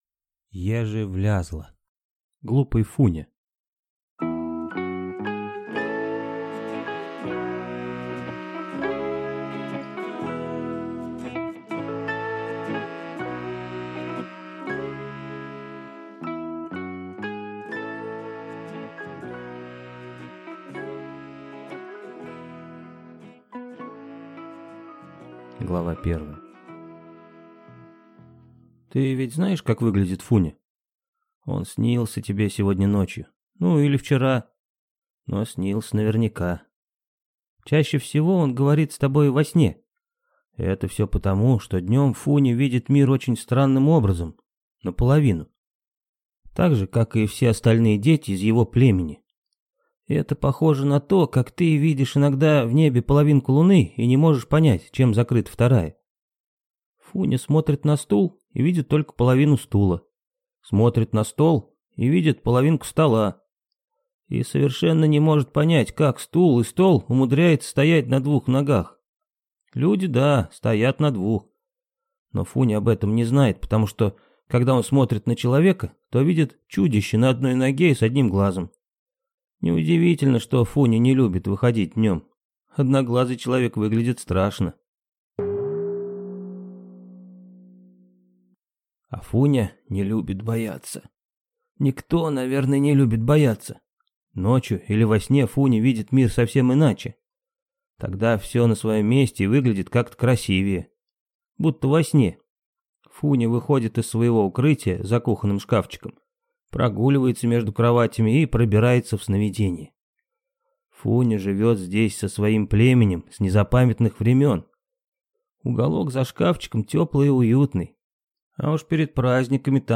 Аудиокнига Глупый Фуня | Библиотека аудиокниг
Прослушать и бесплатно скачать фрагмент аудиокниги